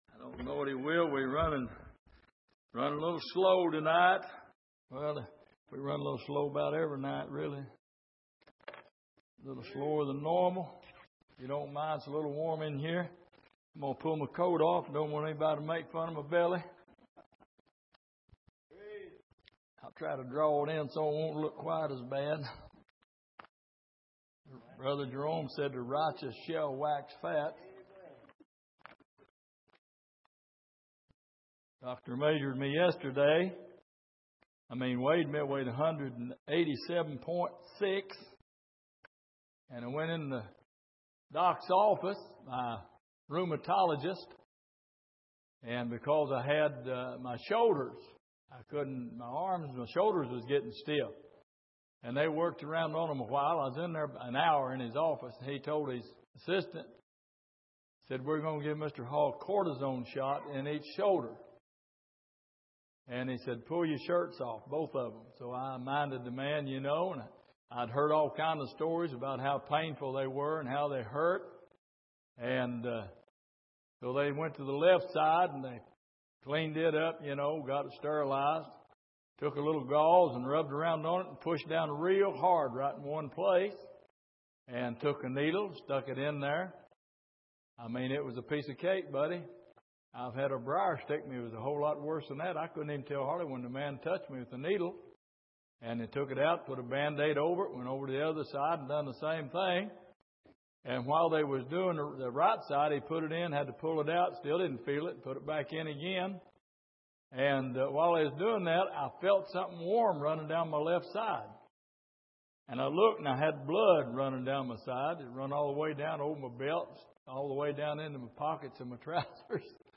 Exposition of the Psalms Passage: Psalm 143:9-12 Service: Midweek